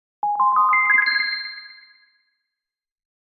Звуки подключения, отключения
Звук подключения для программного обеспечения